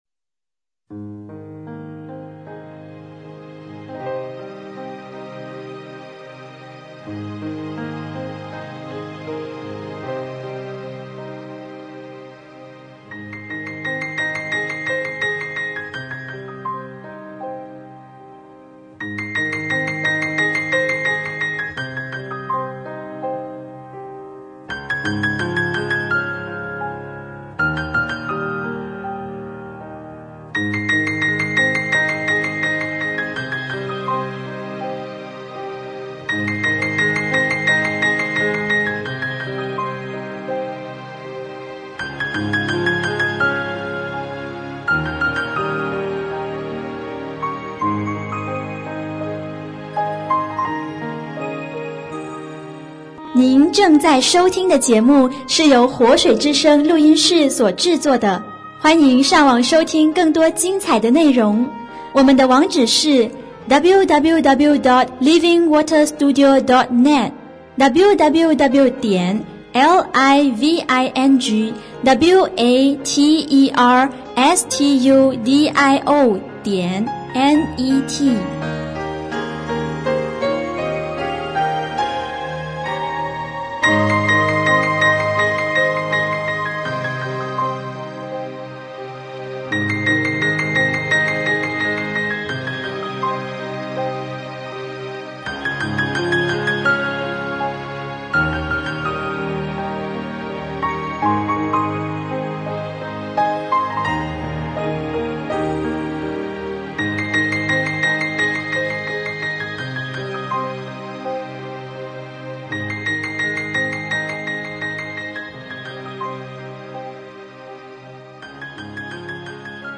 promo-website2015-Female-mix.mp3